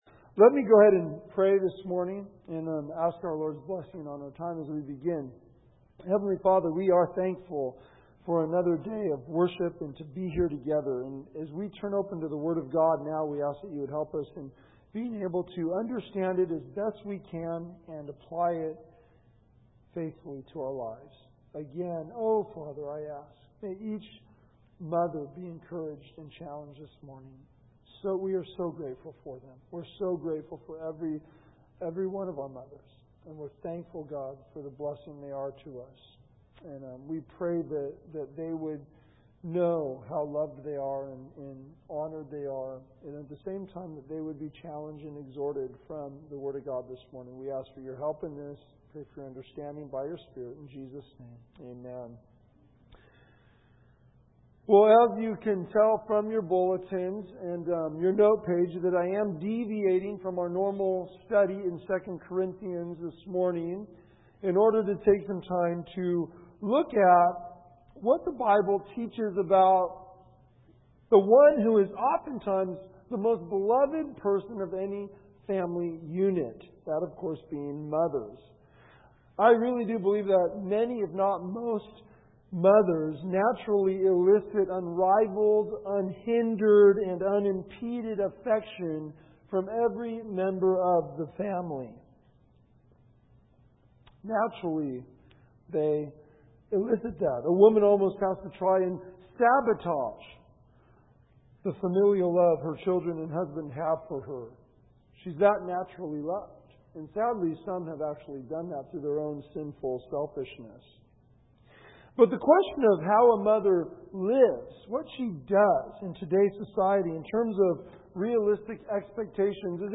Faith Bible Church 1Timothy - Faith Bible Church